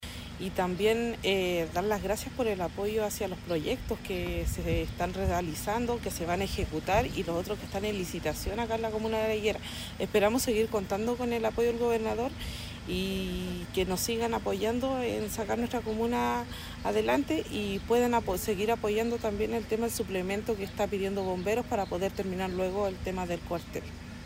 Por su parte, la concejala Ivonne Valenzuela agradeció la visita y recalcó los esfuerzos por mejorar la calidad de vida de los vecinos.
IVONNE-VALENZUELA-CONCEJALA-LA-HIGUERA.mp3